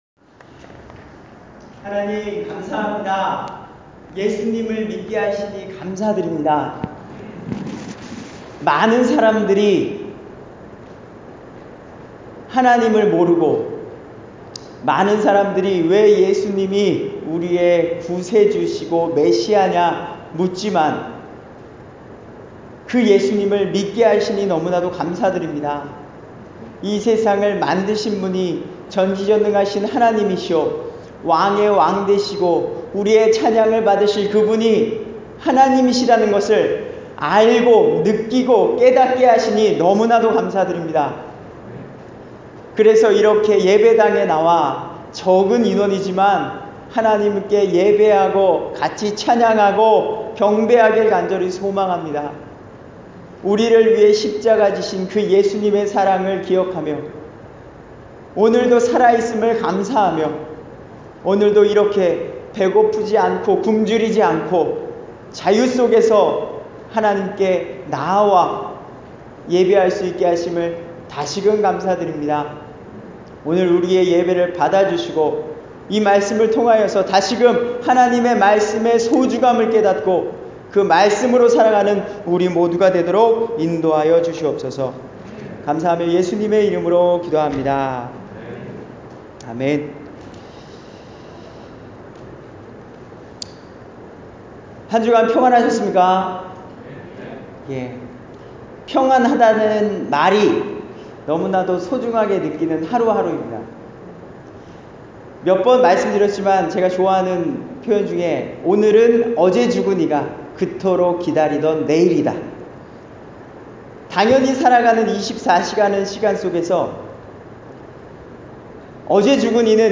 하나님의 말씀을 대하는 두 종류의 사람들-주일설교